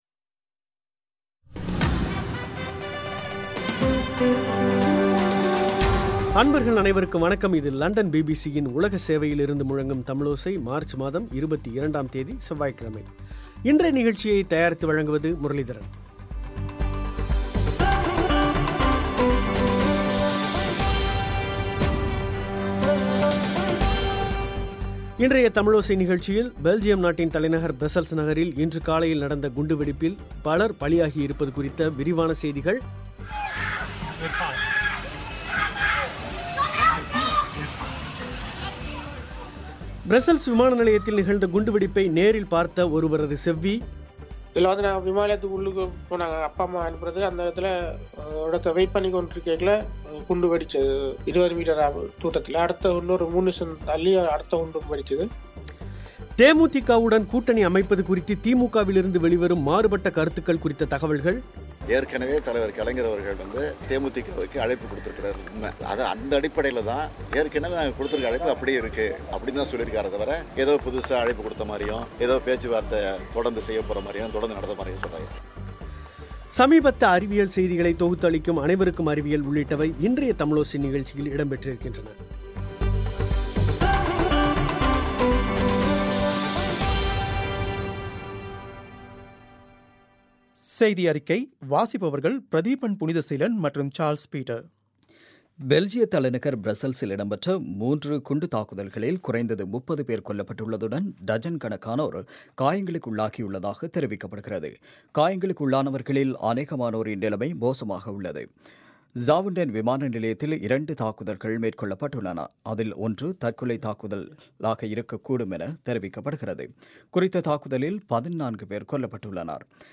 பெல்ஜியம் நாட்டின் தலைநகர் ப்ரஸ்ஸல்ஸ் நகரில் இன்று காலையில் நடந்த குண்டுவெடிப்பில் பலர் பலியாகியிருப்பது குறித்த விரிவான செய்திகள், ப்ரஸ்ஸல்ஸ் விமான நிலையத்தில் நிகழ்ந்த குண்டுவெடிப்பை நேரில் பார்த்த ஒருவரது செவ்வி, தமிழகத்தில் தி.மு.க.- தே.மு.தி.க. கூட்டணி குறித்து மாறுபட்ட கருத்துக்கள் வெளிவருவது குறித்த தகவல்கள், சமீபத்திய அறிவியல் செய்திகளைத் தொகுத்து அளிக்கும் அனைவருக்கும் அறிவியல் உள்ளிட்டவை இன்றைய தமிழோசை நிகழ்ச்சியில் இடம்பெற்றிருக்கின்றன.